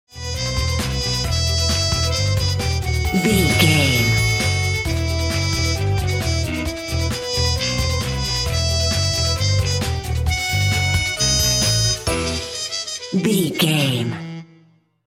Thriller
Aeolian/Minor
Fast
aggressive
driving
dark
dramatic
energetic
groovy
frantic
suspense
horns
violin
bass guitar
drums
strings
trumpet
brass
percussion
70s